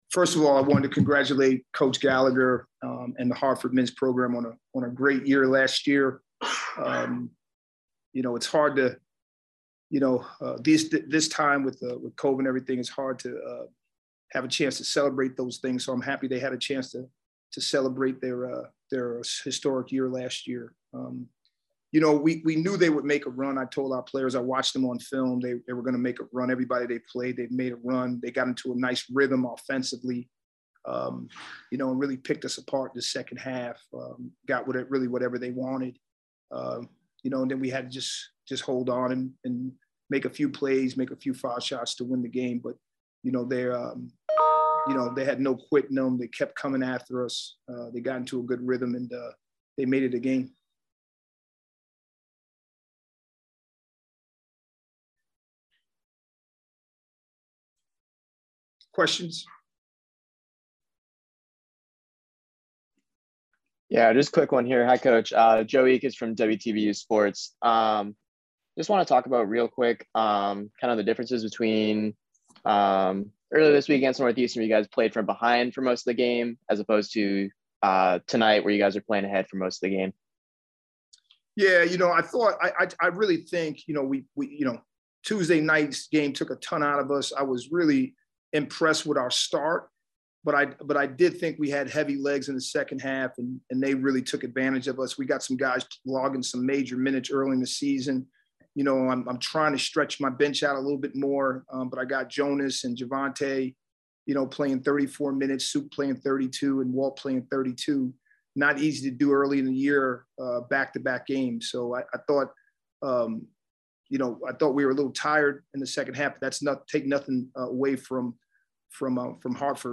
Hartford_press_conference.mp3